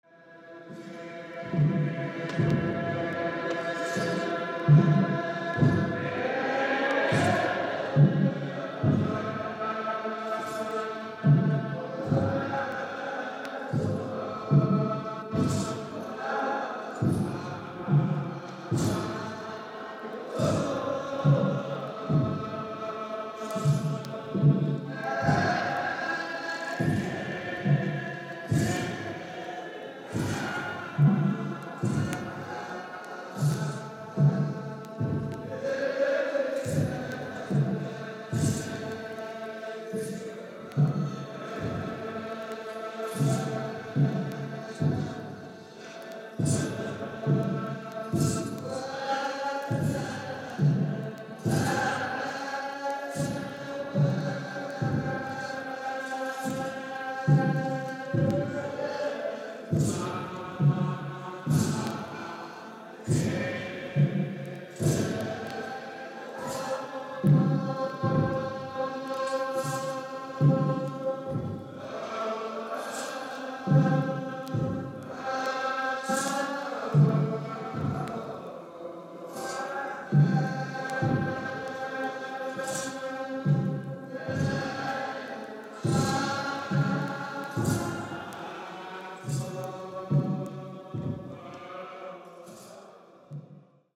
エリトリア・主要９民族（アファル族、ビレン族、ヘダレブ族、クナマ族、ナラ族、ラシャイダ族、サホ族、ティグレ族、ティグリニャ族）
エチオピアでまとめるには奥の深いサウンドです！！！